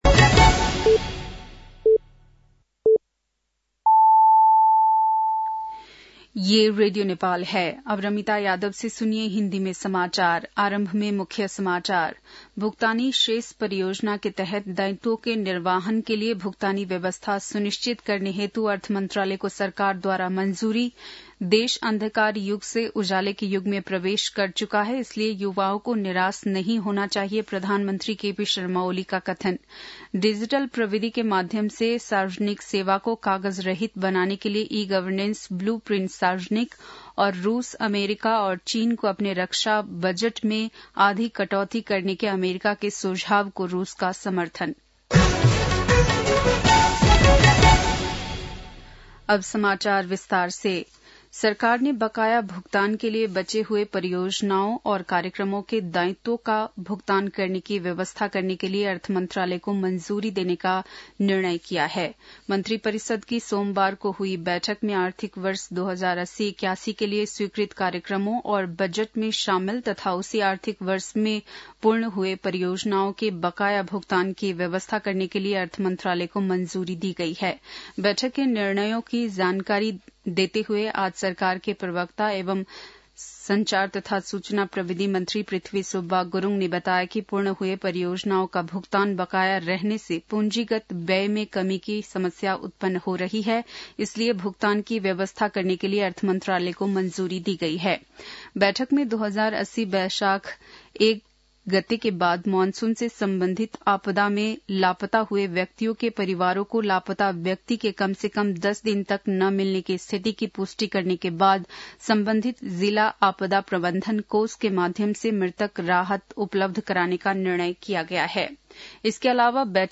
बेलुकी १० बजेको हिन्दी समाचार : १४ फागुन , २०८१